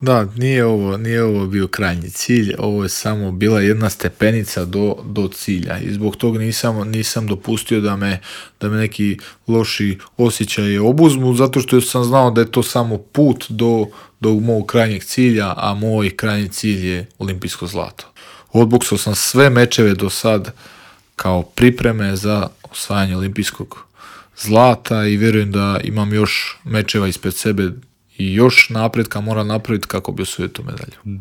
O svom putu do svjetske bronce govorio je naš najbolji boksač u Intervjuu Media servisa.